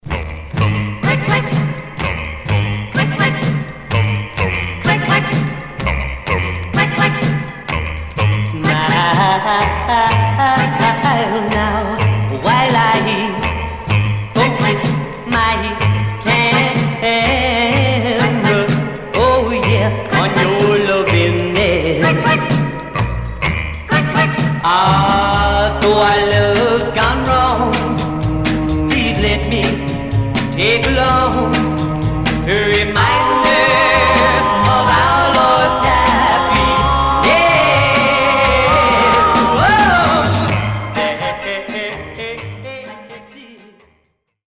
Stereo/mono Mono
Teen